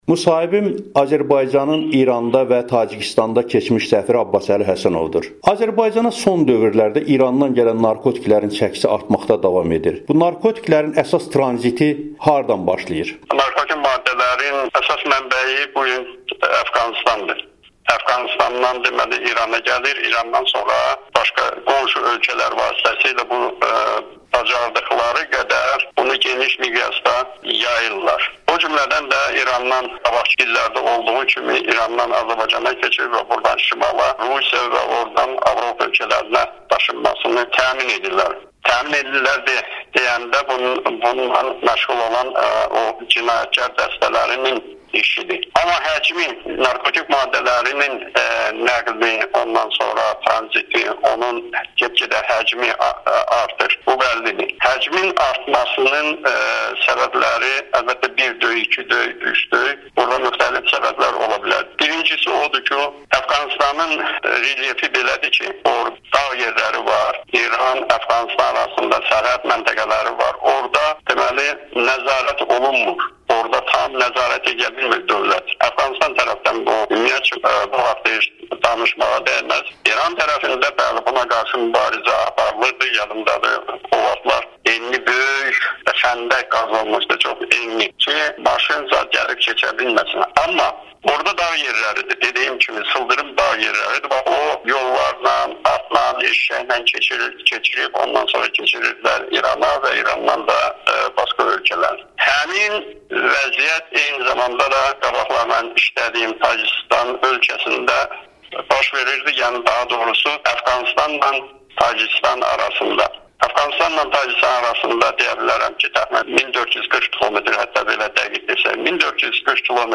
Həsənov Amerikanın Səsinə müsahibəsində deyib ki, regionda narkotikin son illərdə geniş yayılmasının əsas səbəbi Əfqanıstanda və Orta Şərqdə yaranain hərbi-siyasi böhrandır.
Abbasəli Həsənov, keçmiş səffir